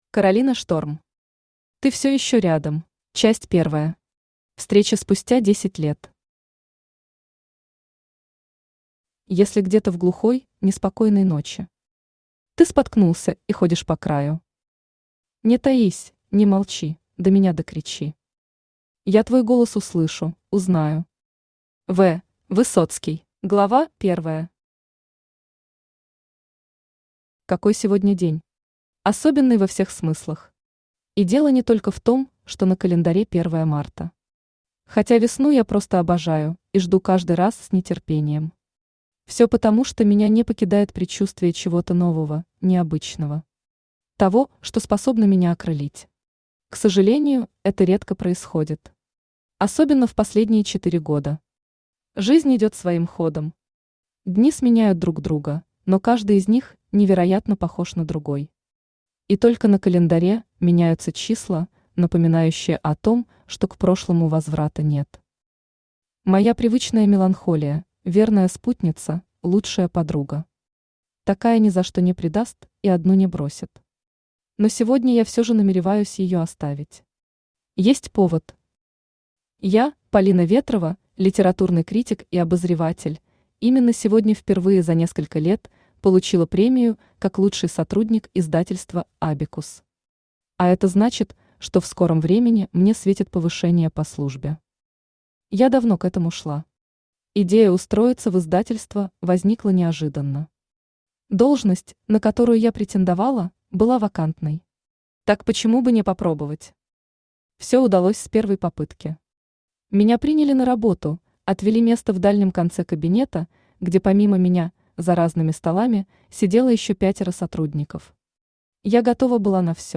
Аудиокнига Ты всё ещё рядом | Библиотека аудиокниг
Aудиокнига Ты всё ещё рядом Автор Каролина Шторм Читает аудиокнигу Авточтец ЛитРес.